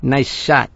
gutterball-3/Gutterball 3/Commentators/Louie/l_niceshot.wav at 620778f53e7140d9414cafde9e18367a6aeae46f
l_niceshot.wav